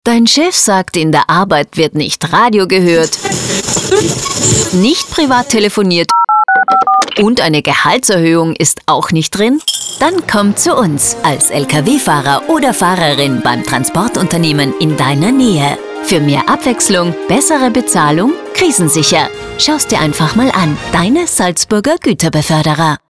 >Radiospot zur Fahrer:innen Suche